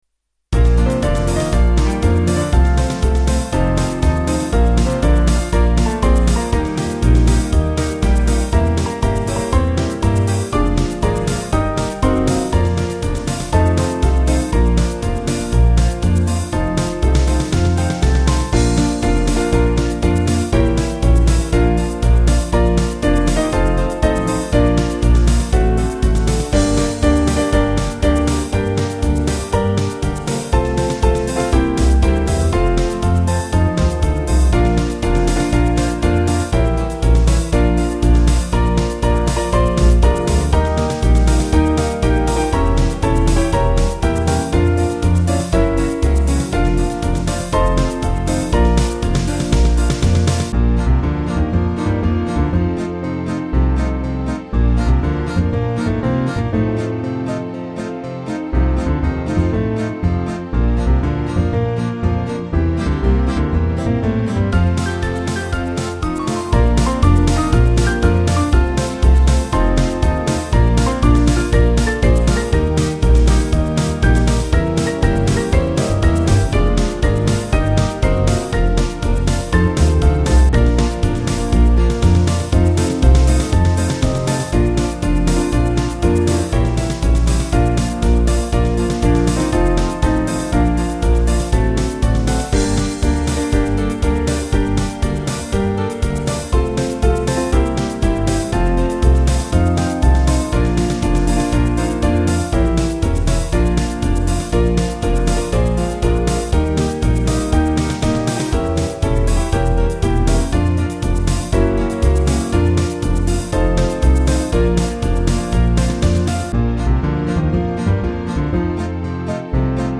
Small Band
Faster   396kb